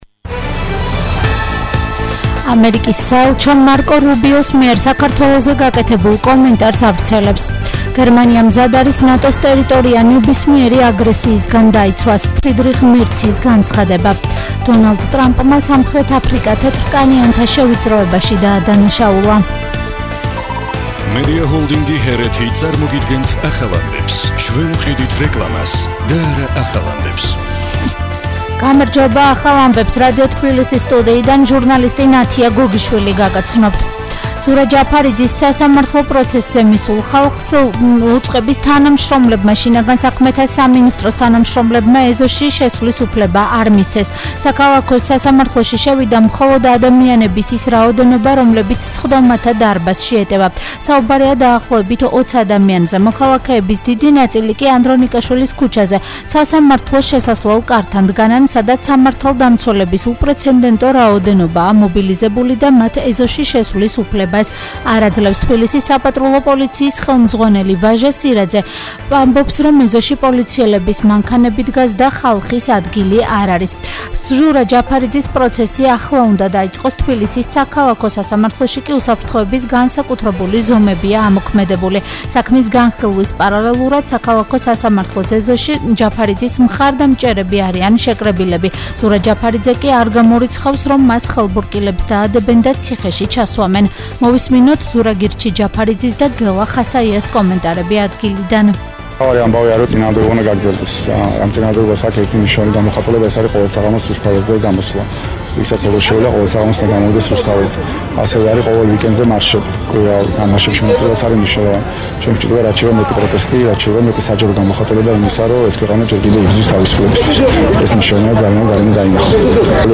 ახალი ამბები 15:00 საათზე